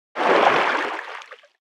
File:Sfx creature seamonkeybaby swim slow 04.ogg - Subnautica Wiki
Sfx_creature_seamonkeybaby_swim_slow_04.ogg